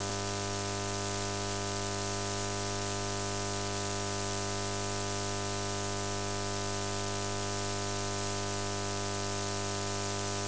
Начало » Записи » Радиоcигналы на опознание и анализ